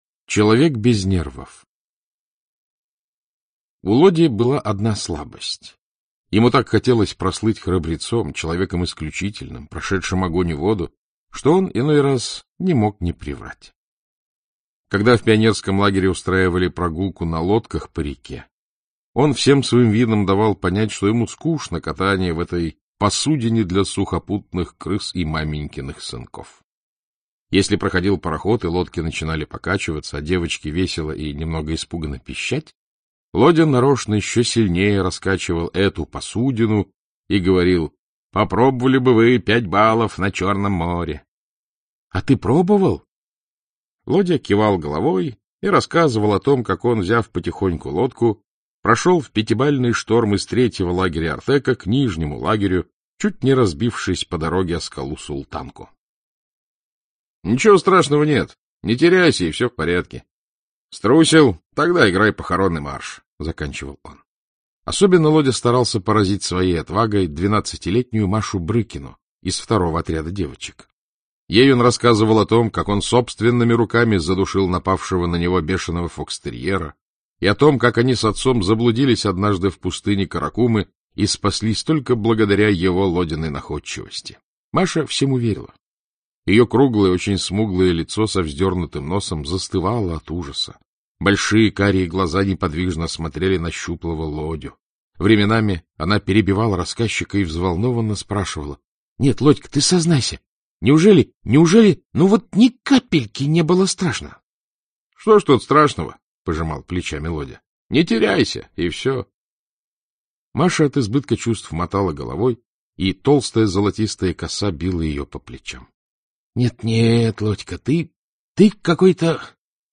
Аудиокнига Как я был самостоятельным | Библиотека аудиокниг